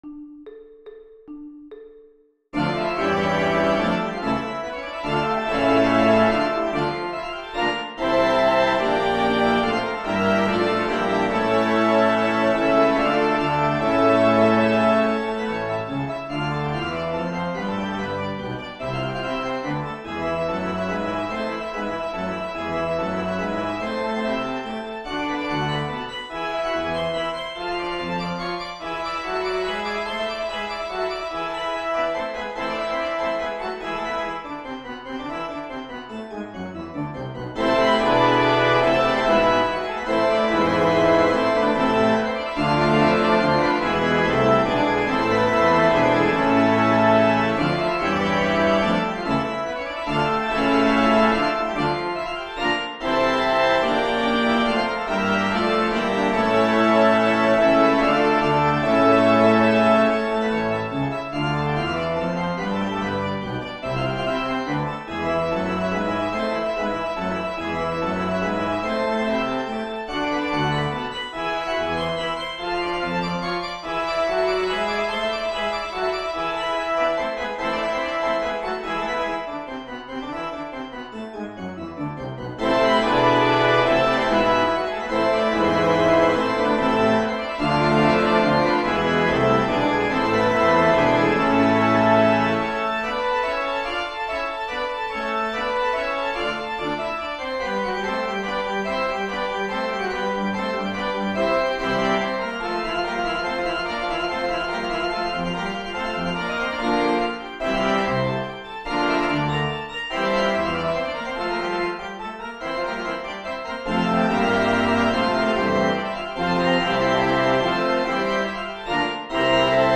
Demo 4: Allegro